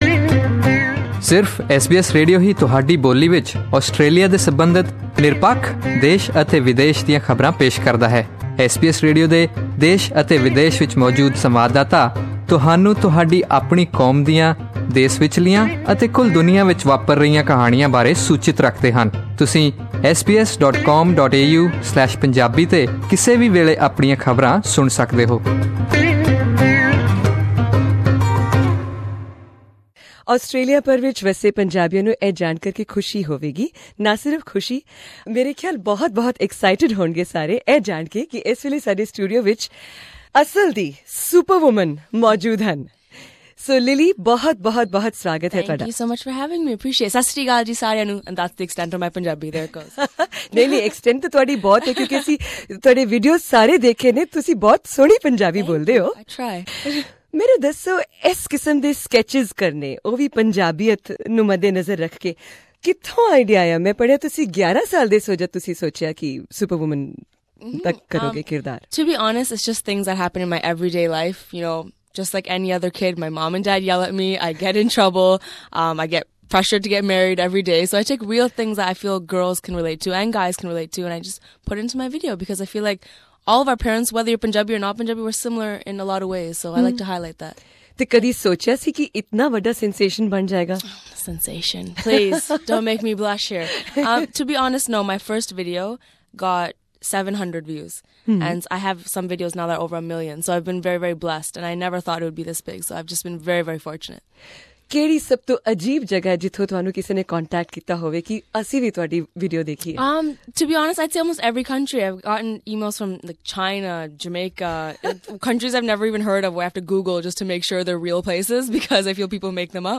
Here is a fascinating interview with 23-year-old Lilly Singh, probably the most popular female entertainer of South Asian origin in the world!
She stopped by the SBS Melbourne studios during her visit and apart from entertaining us in this interview, she gave us an insight on "Who is Lilly Singh?", whether she is reinforcing stereotypes about Punjabi culture, whether she is a proud Punjaban, and also - what should Punjabis learn from western world, and vice versa...what should the western world adopt from the Punjabi culture.